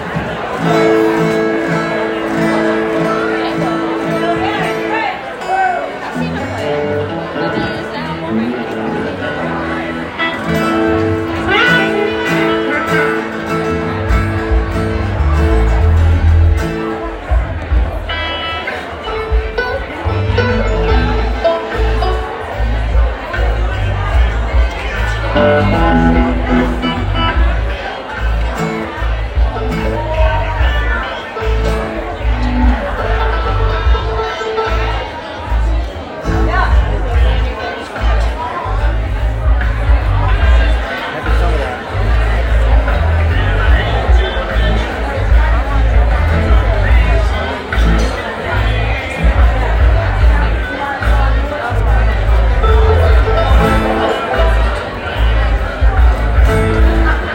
lifeblood: bootlegs: 2023-02-13: tipitina's - new orleans, louisiana (amy ray)